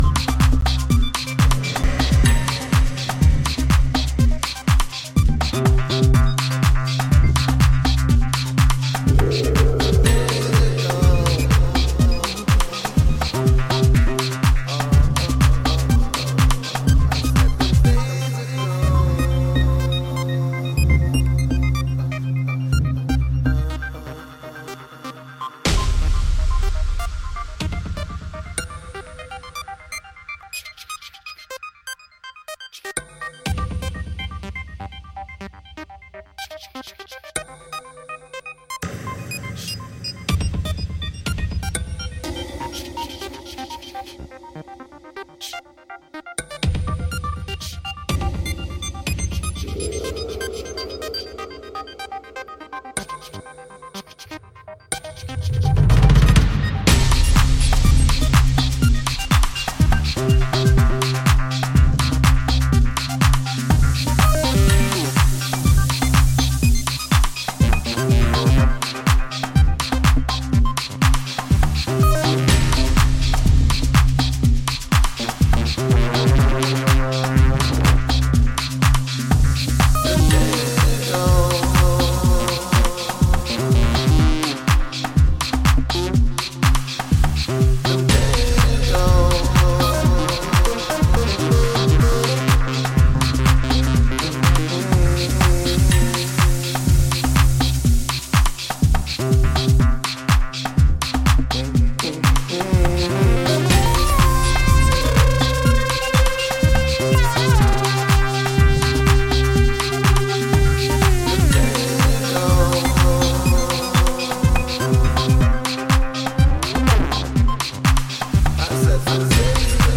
Dance Version